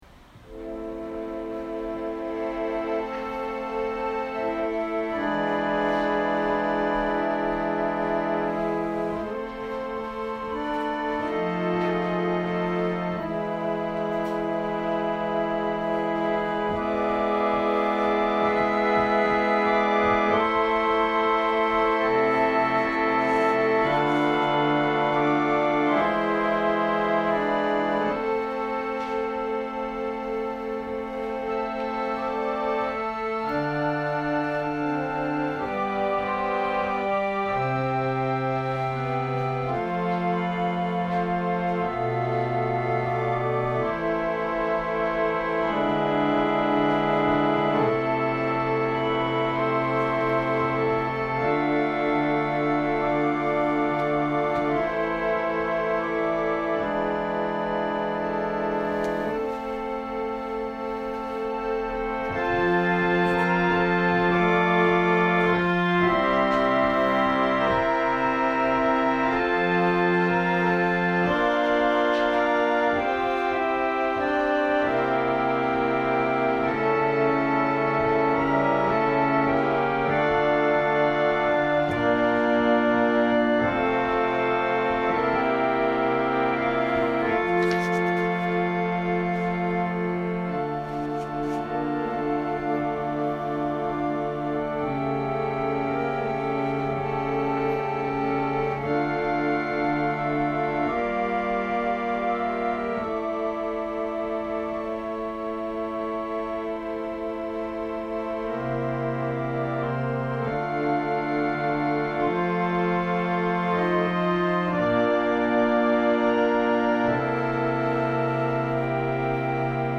2023年02月19日朝の礼拝「テモテとエパフロディト 디모데와 에바브로디도」せんげん台教会
千間台教会。説教アーカイブ。